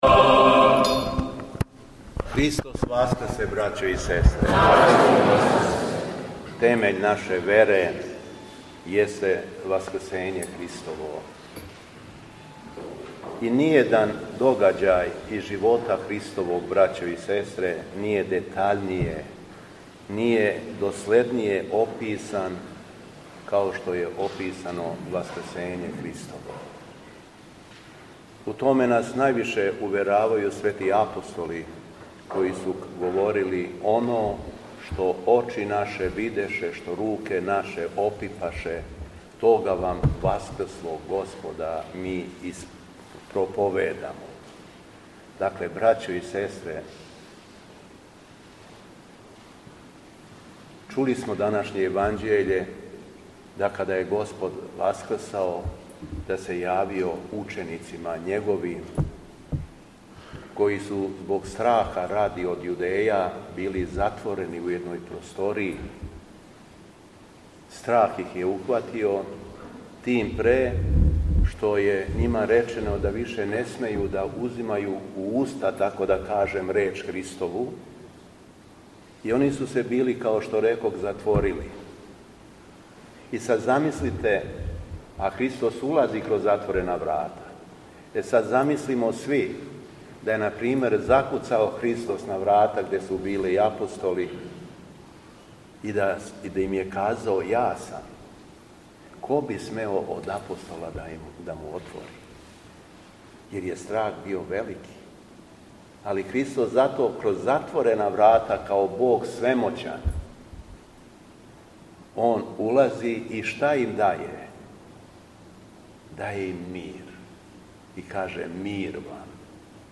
Беседа Његовог Високопреосвештенства Митрополита шумадијског г. Јована
Дана 19. априла лета Господњег 2026. на Томину недељу, Његово високопреосвештенство Митрполит шумадијски Господин Јован началствовао је свештеним евхаристијским сабрањем у крагујевачком насељу Илићеву у храму Светог Апостола Томе.